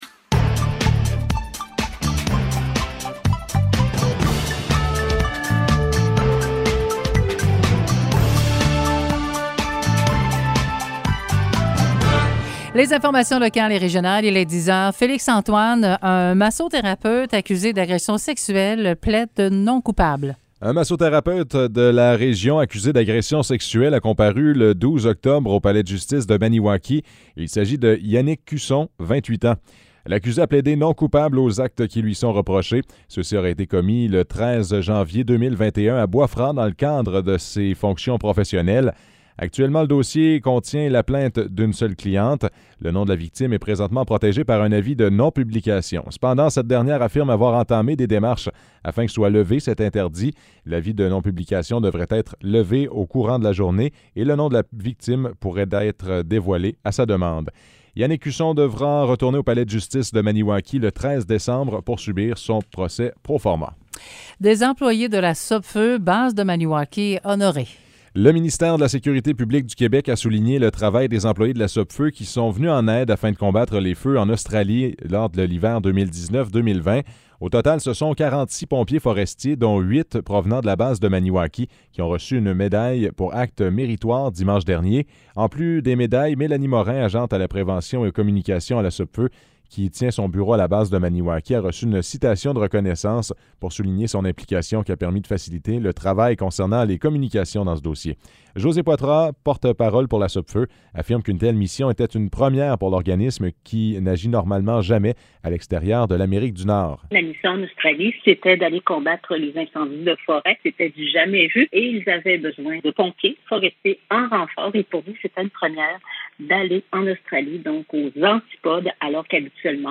Nouvelles locales - 14 octobre 2021 - 10 h